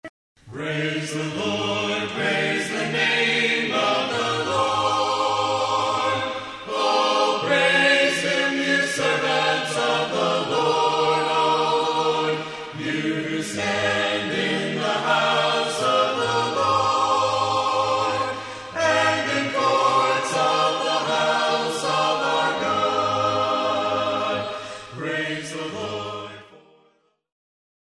praise